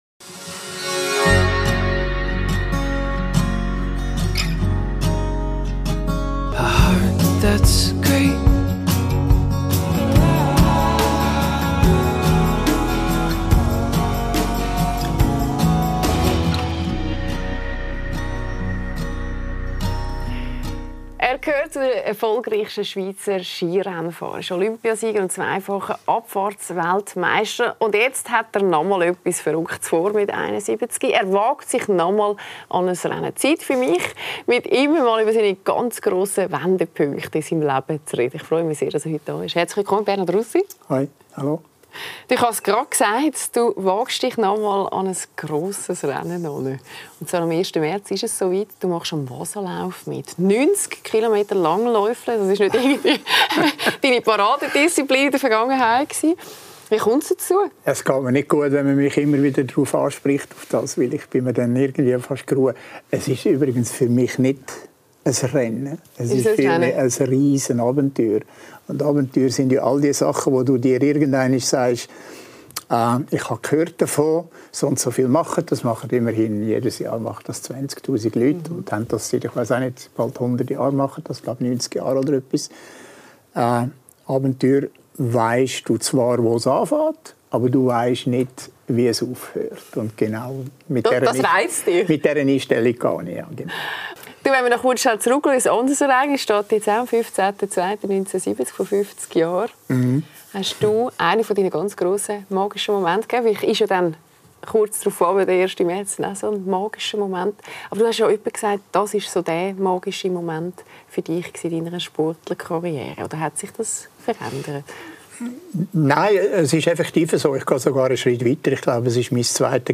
Lässer Classics zeigt die besten Talks aus den letzten zehn Jahren mit Claudia Lässer. In dieser Folge: Bernhard Russi.